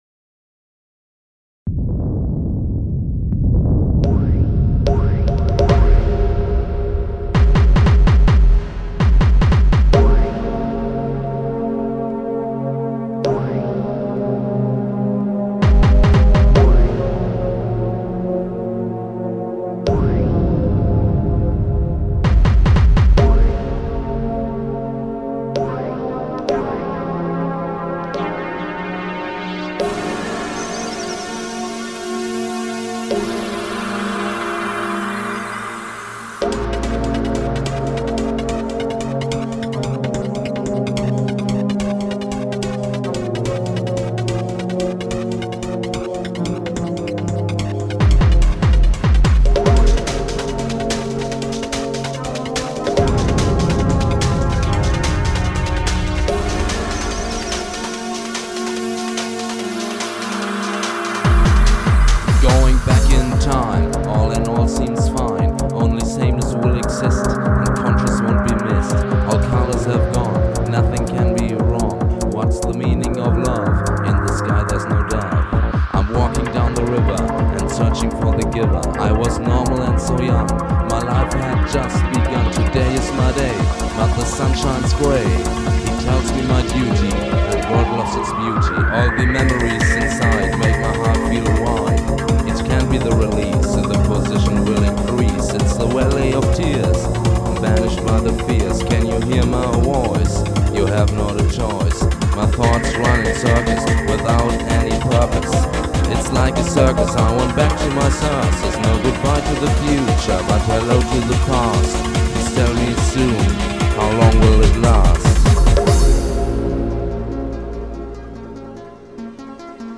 Recorded at the Music Recording Studio